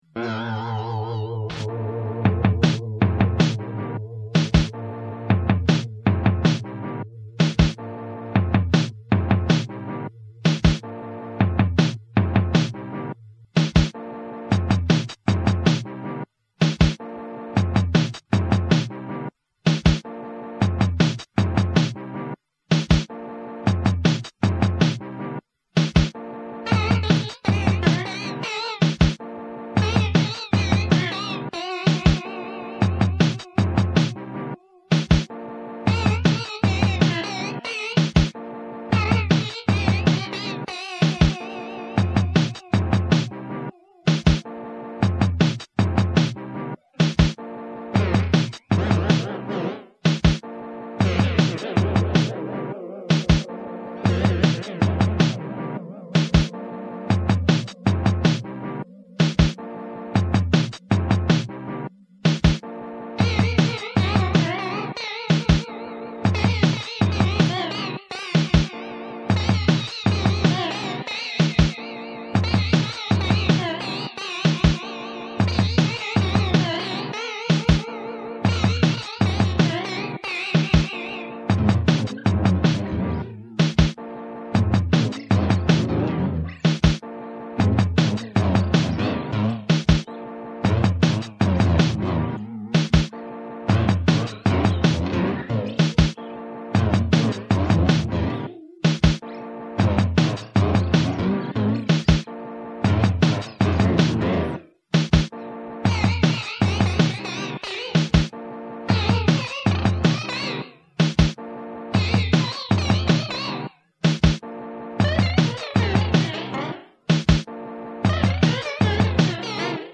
casio drum machine
martian guitar